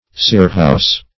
Meaning of scirrhous. scirrhous synonyms, pronunciation, spelling and more from Free Dictionary.
scirrhous.mp3